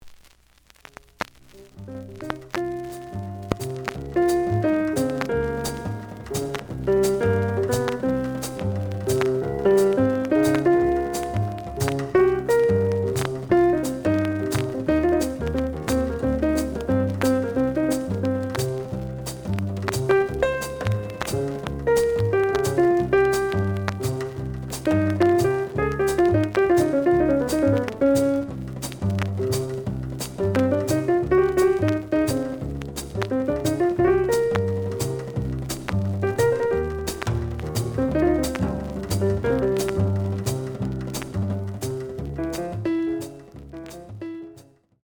The audio sample is recorded from the actual item.
●Genre: Hard Bop
Some click noise on B side due to scratches.